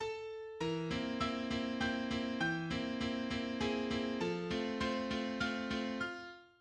Opening measures
The first five notes of the theme are A, B (B), E, G, and G. This use of pitch names as letters was also used by Schumann in other compositions, such as his Carnaval.
Thema (Animato) (F major)